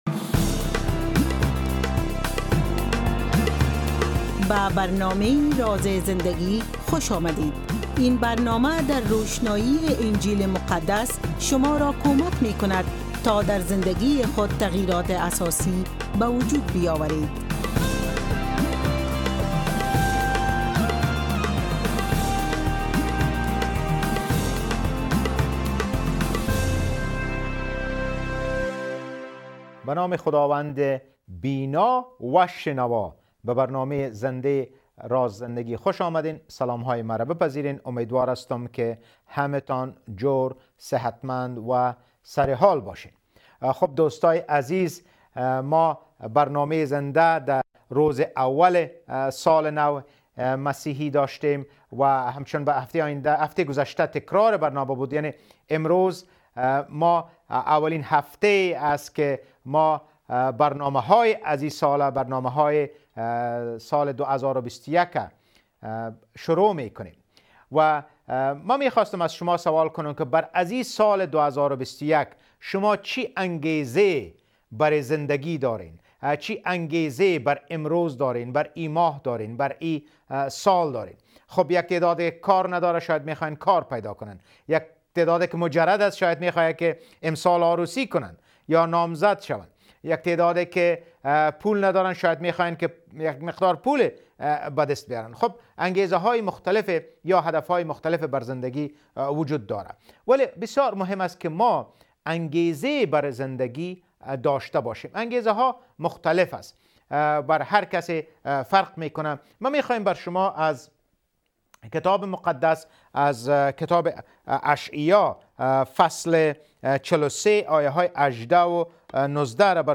This new series called Rivka is based on a drama that has been translated and dubbed into Dari. Today’s episodes talks about challenges in the family.